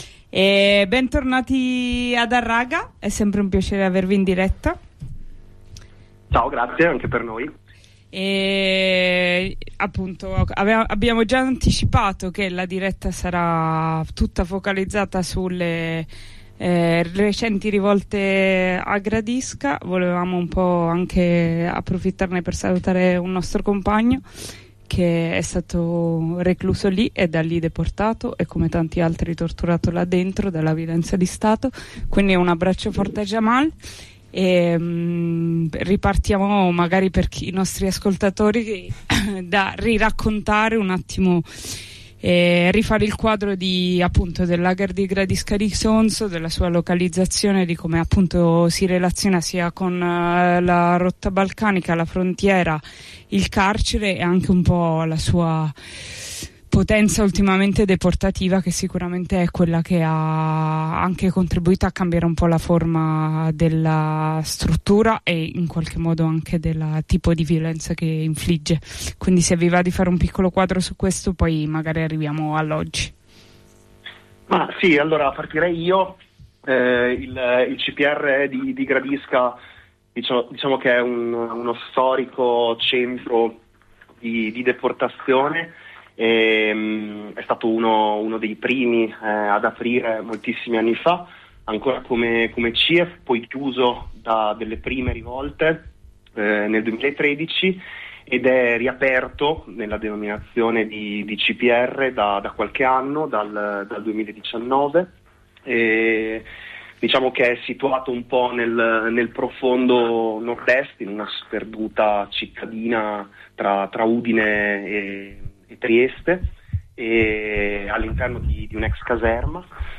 Harraga – trasmissione in onda su RadioBlackout, ogni venerdì dalle 15 alle 16 – tenta di opporsi alla silenziazione a cui i rivoltosi sono sottoposti, restituendo la complessità storico-politico-geografica di ogni momento di lotta. Ai microfoni alcunx compagnx di quella zona d’Italia, ci raccontano la storia del lager, le ultime vicende e l’affilarsi della lama repressiva.